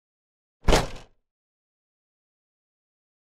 Tiếng Đóng Cốp xe Ô Tô (mạnh)
Thể loại: Tiếng xe cộ
Description: Tiếng đóng cốp xe ô tô mạnh, âm thanh “rầm” hoặc “cạch” vang dội khi cốp sau xe được đóng dứt khoát, tạo cảm giác chắc chắn, kiên cố. Đây là loại âm thanh đặc trưng, rõ ràng, thường được dùng trong dựng phim, chỉnh sửa video để nhấn mạnh hành động đóng cốp xe, tăng hiệu ứng chân thực và kịch tính.
tieng-dong-cop-xe-o-to-manh-www_tiengdong_com.mp3